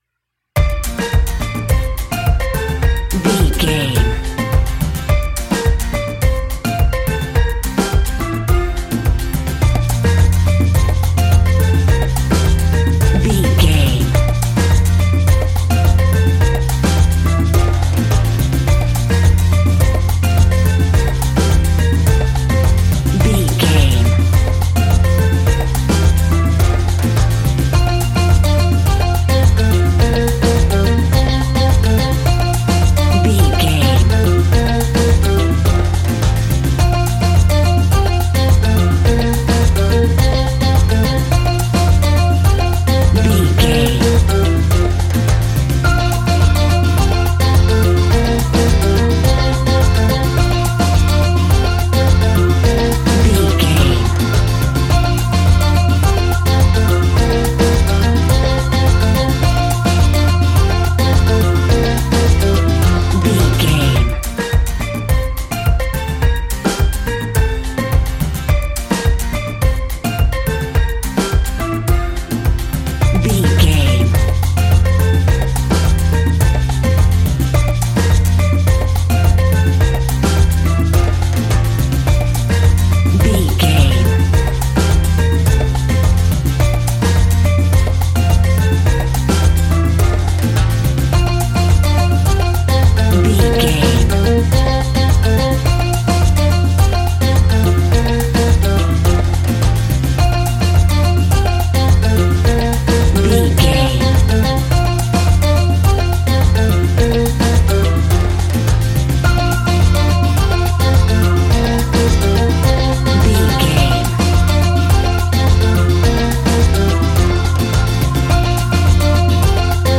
That perfect carribean calypso sound!
Uplifting
Ionian/Major
calypso music
steel pan
drums
percussion
bass
brass
guitar